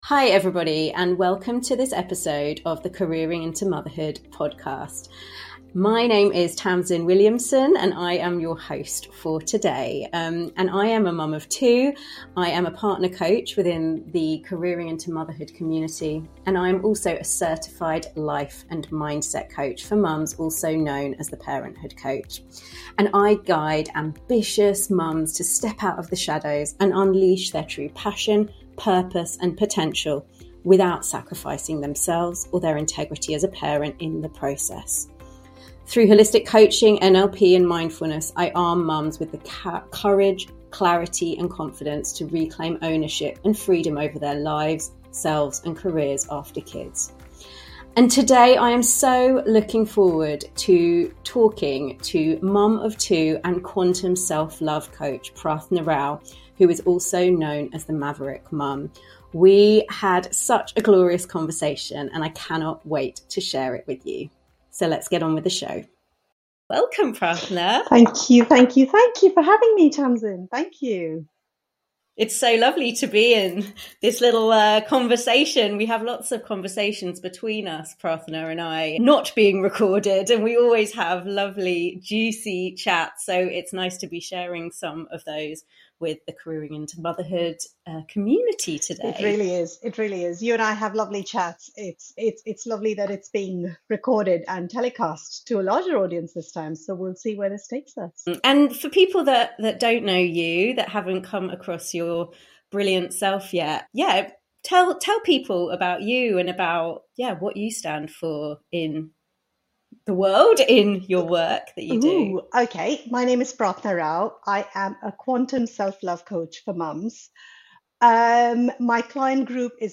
heartfelt conversation